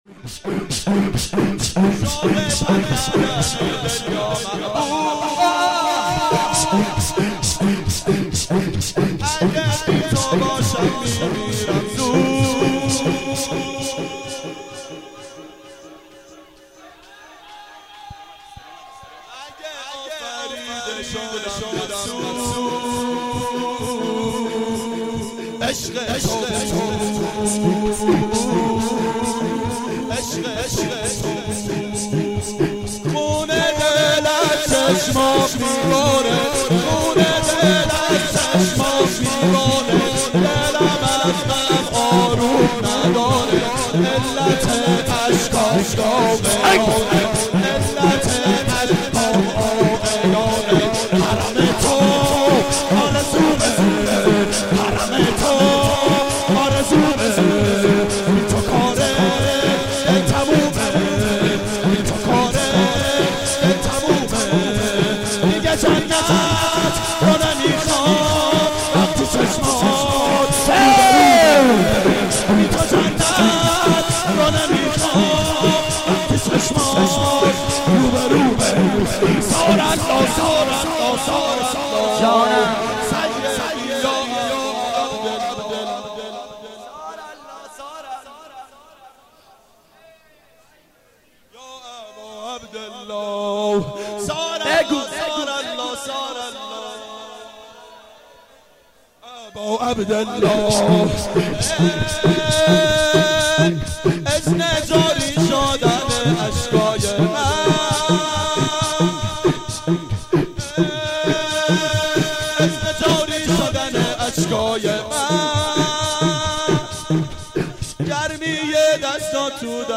• شام غریبان حضرت زهرا سلام الله علیها 89 هیئت محبان امام حسین علیه السلام شهر اژیه